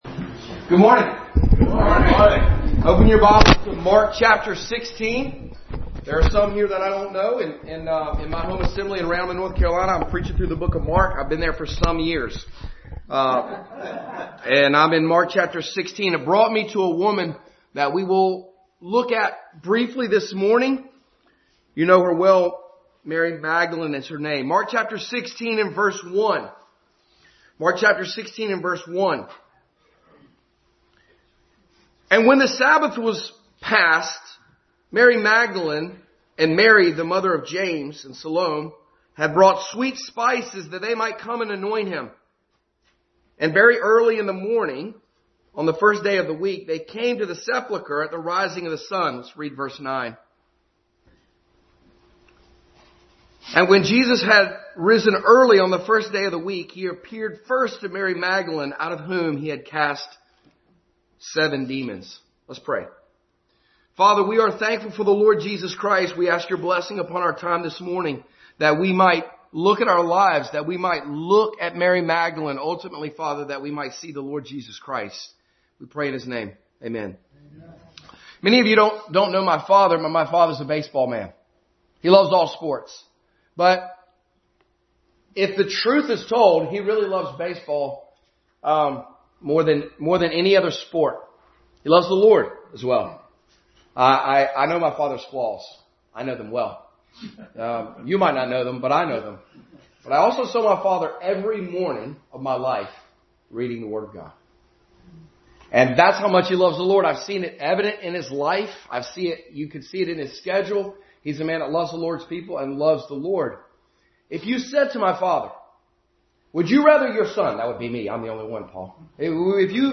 Mary Magdalene Passage: Mark 16:1-9, 15:40, Luke 8:1-3, Ezekiel 44, 45 Service Type: Sunday School